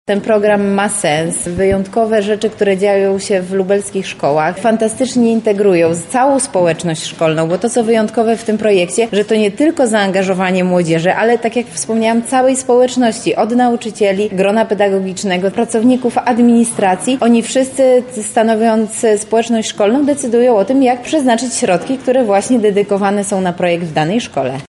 -mówi Beata Stepaniuk-Kuśmierzak, Zastępczyni Prezydenta Lublina ds. Kultury, Sportu i Partycypacji.